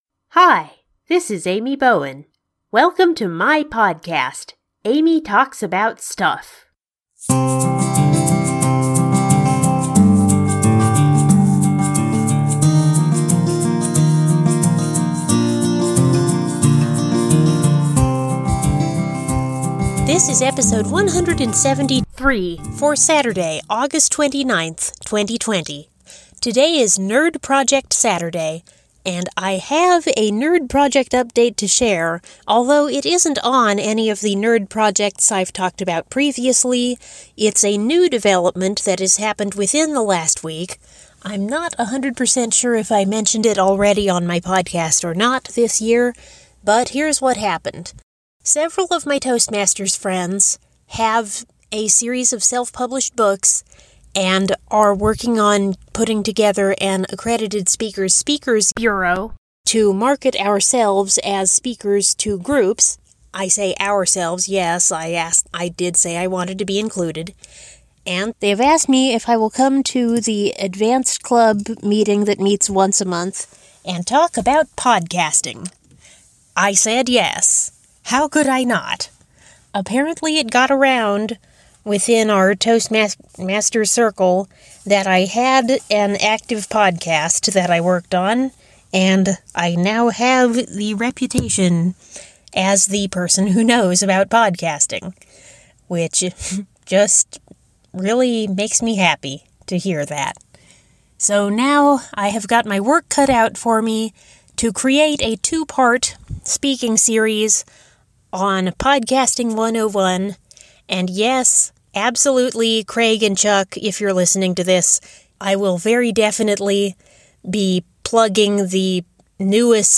It’s Nerd Project Saturday, and I have a whole new nerd project to work on. This episode also contains another set of on-location recordings from Nebraska Passport stops.